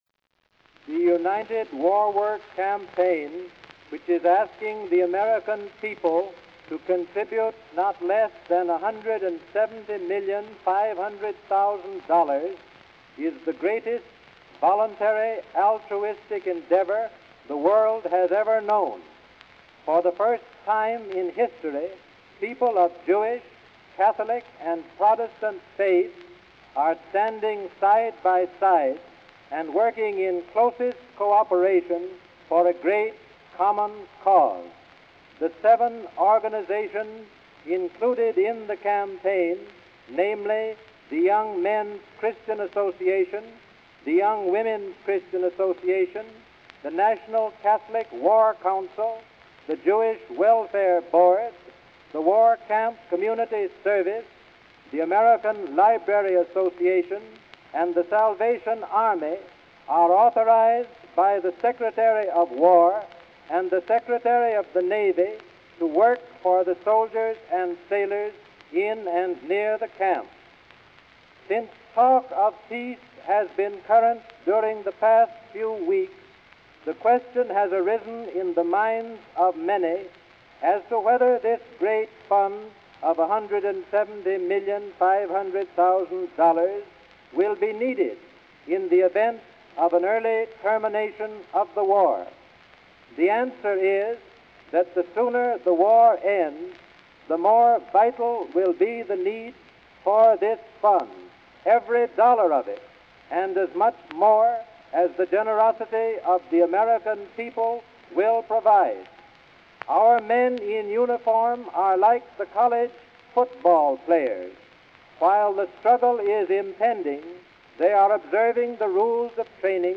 G. Robert Vincent Voice Library Collection